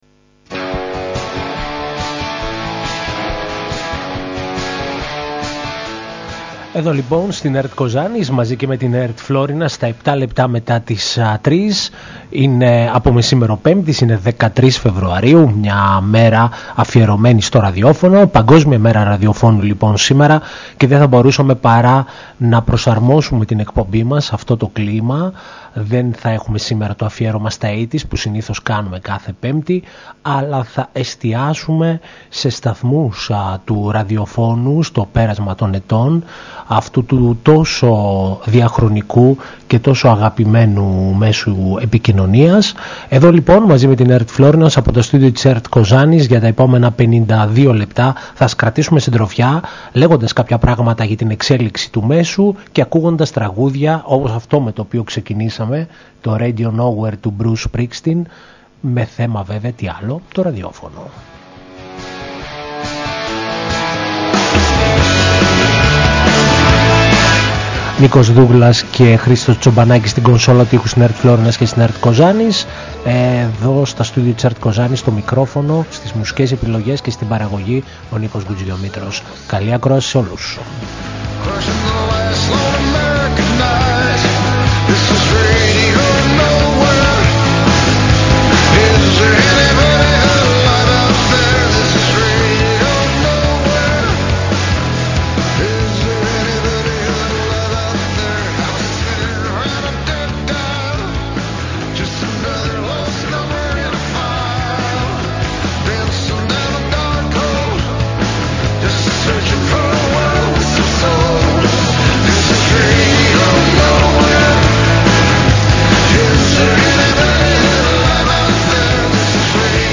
Η εκπομπή είναι διανθισμένη με τραγούδια από το διεθνή χώρο με θέμα το ραδιόφωνο.